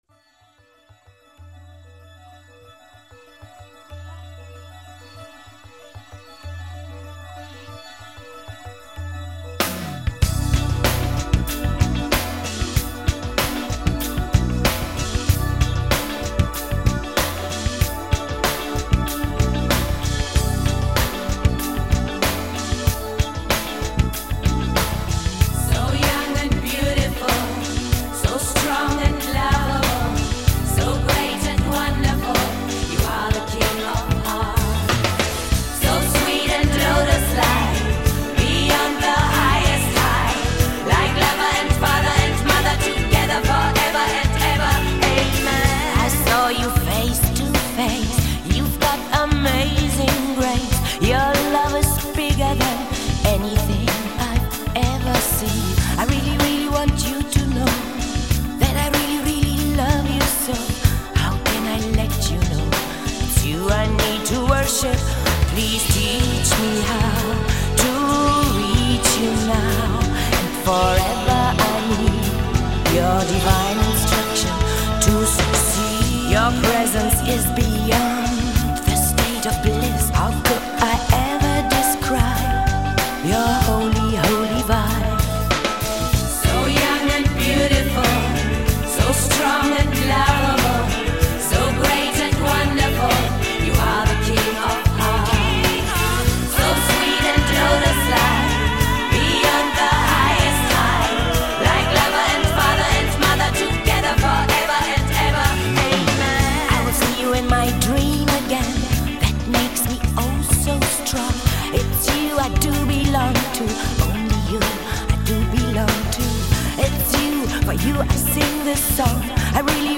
Панк Рок